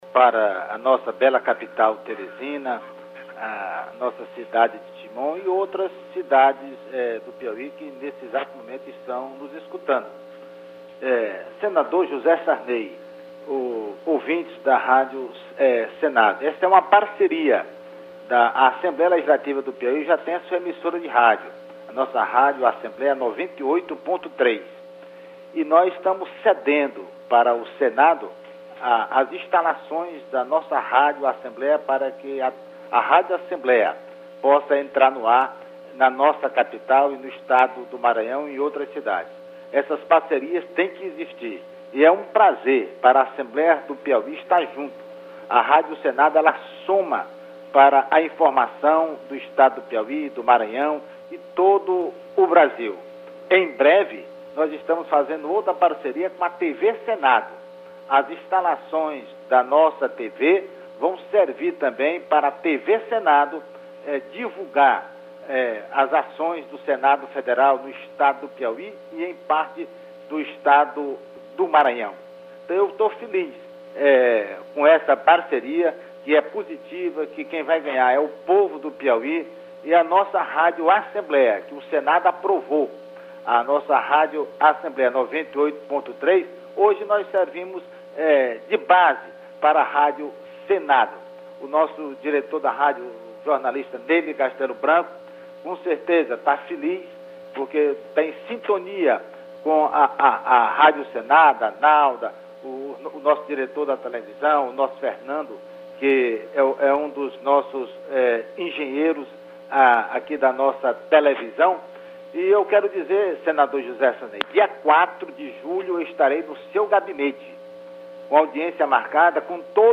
Pronunciamento do deputado estadual Themístocles Filho
Tópicos: Pronunciamento